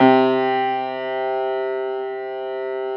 53h-pno06-C1.wav